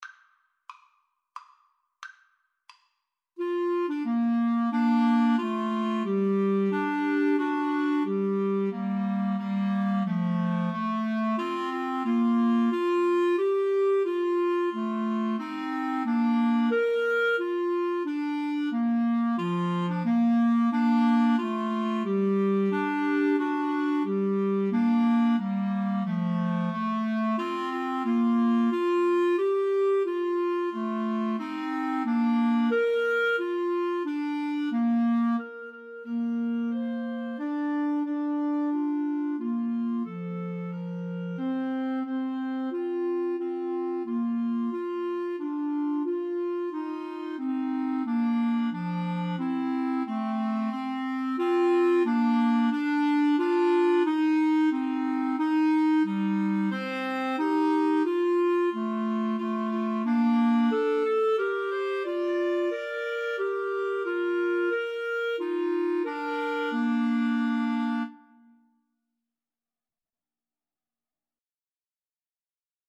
Bb major (Sounding Pitch) C major (Clarinet in Bb) (View more Bb major Music for Clarinet Trio )
Maestoso = c.90
3/4 (View more 3/4 Music)
Clarinet Trio  (View more Intermediate Clarinet Trio Music)